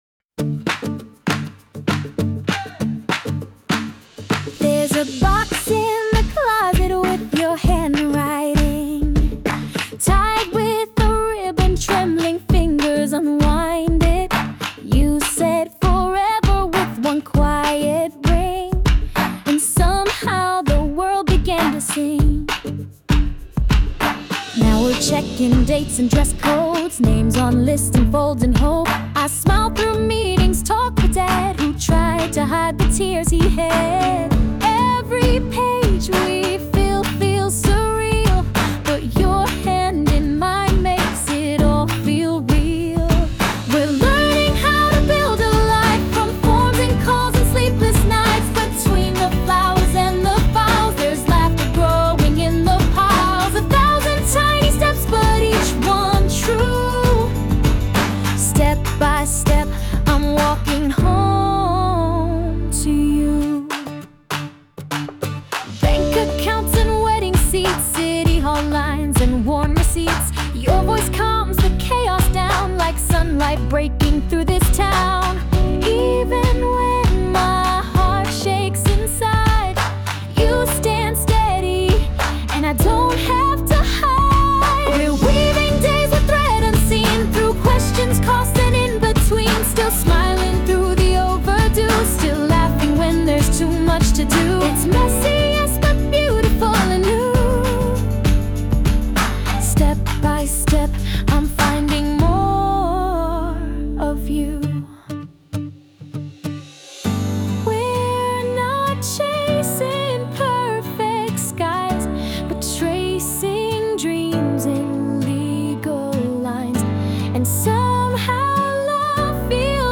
洋楽女性ボーカル著作権フリーBGM ボーカル
著作権フリーオリジナルBGMです。
女性ボーカル（洋楽・英語）曲です。
リアルに、でも優しく描いたラブソングです💛